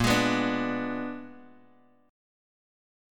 Bb7b9 Chord
Listen to Bb7b9 strummed